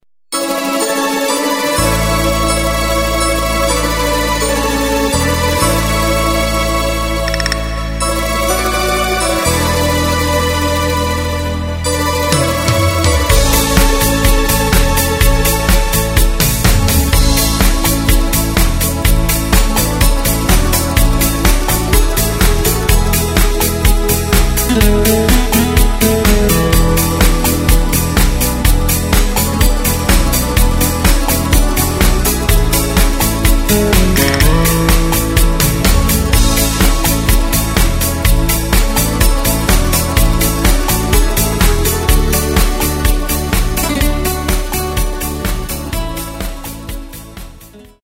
Takt:          4/4
Tempo:         125.00
Tonart:            A
Schlager aus dem Jahr 2011!
Playback mp3 Demo